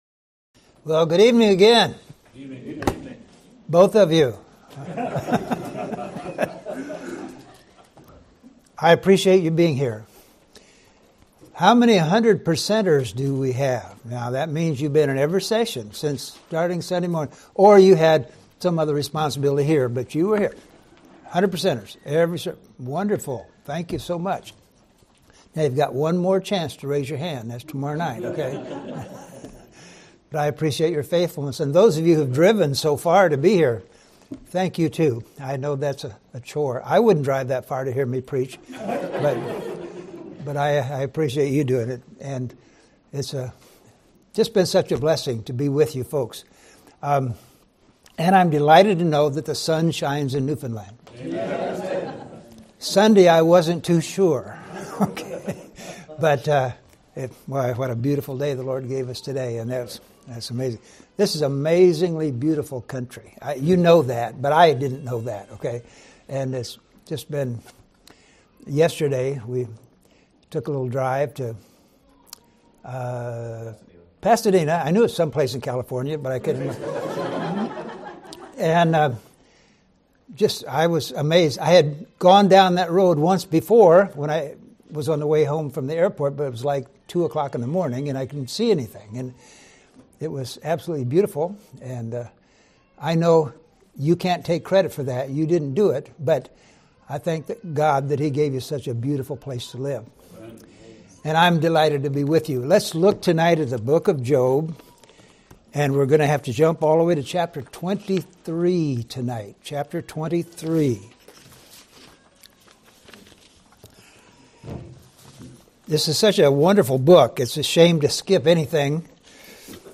Sermons: Finding God in the Midst of Trouble
Show Details → Sermon Information Title Finding God in the Midst of Trouble Description Message #4 of the 2025 Bible Conference. God is always at work in our lives, even when we cannot perceive it. In the midst of trouble, we must find God's work, God's wisdom, God's way, God's Word, and God's will.